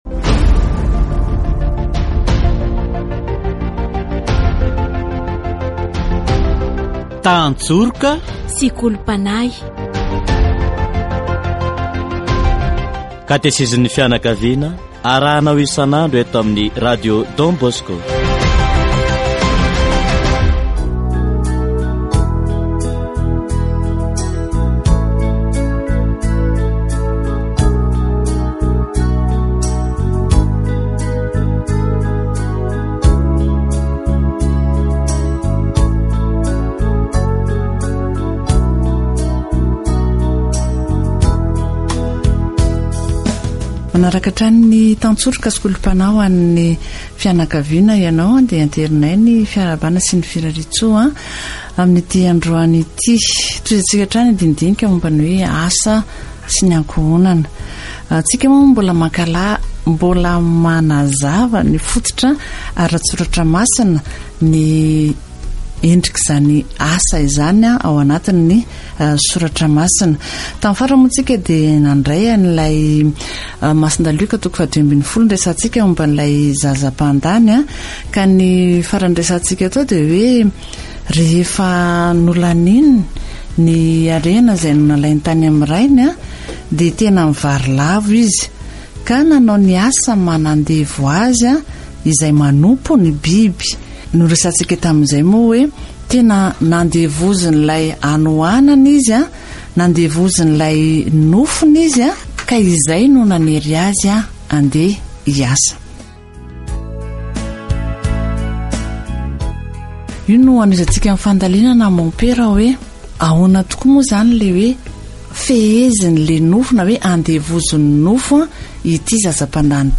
Katesizy momba ny asa